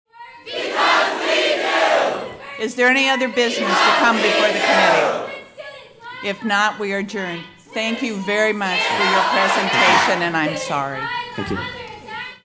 During the regents’ Wednesday meeting in Iowa City, protesters organized by the union shut down proceedings. Regents President Pro Tem Sherry Bates had to raise her voice to adjourn the meeting.